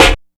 kits/RZA/Snares/WTC_SNR (16).wav at 32ed3054e8f0d31248a29e788f53465e3ccbe498